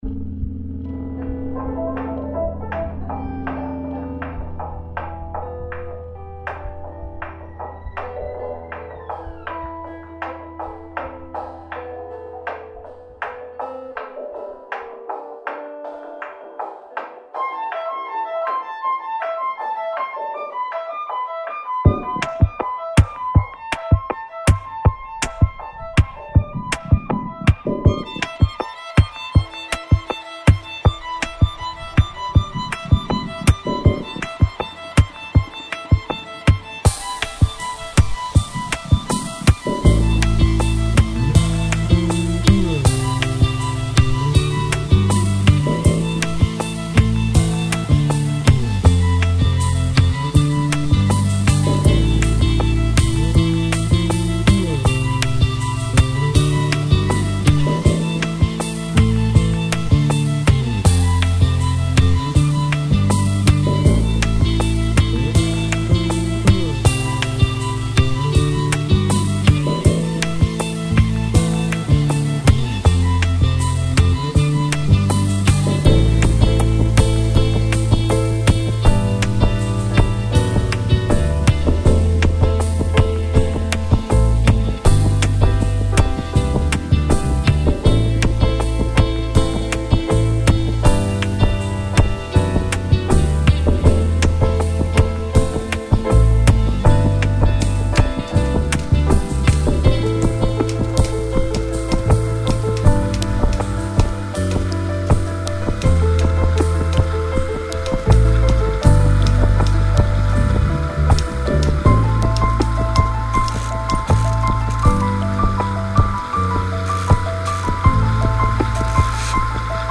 File under: Post-Rock / Electronic / Experimental